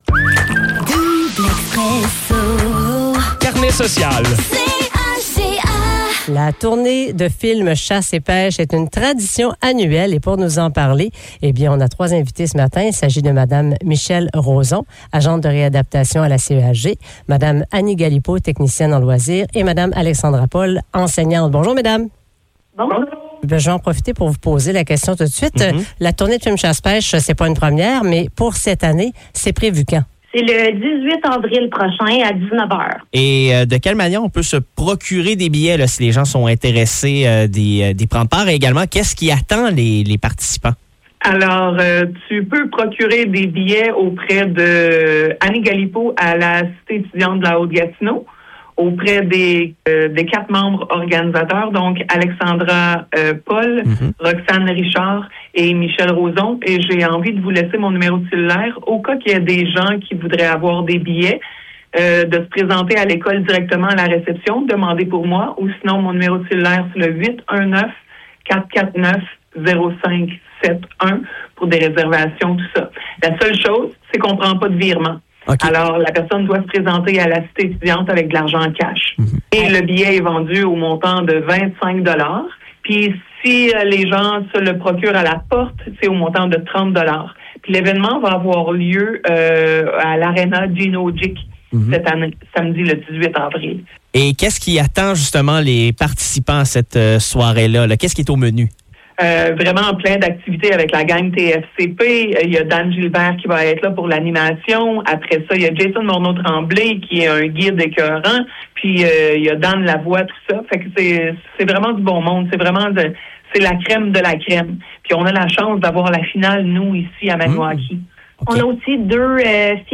Épisode Entrevue Carnet social
Entrevue avec les organisatrices de la Tournée de films chasse et pêche (mise de l’avant par Bête de chasse) au Centre sportif Gino Odjick, qui invitent la population à participer à cette campagne de financement.